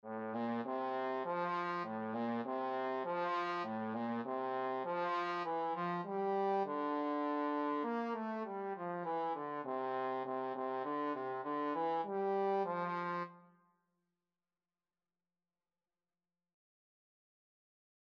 3/4 (View more 3/4 Music)
Moderato
F major (Sounding Pitch) (View more F major Music for Trombone )
Trombone  (View more Easy Trombone Music)
Traditional (View more Traditional Trombone Music)
Mexican